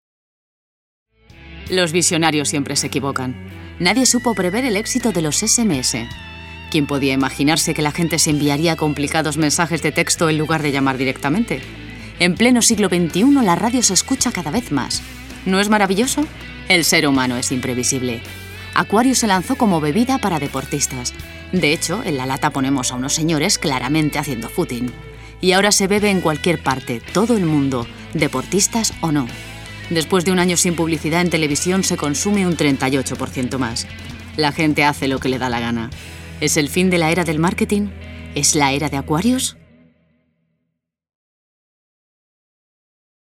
Voz cálida y expresiva.
kastilisch
Sprechprobe: Industrie (Muttersprache):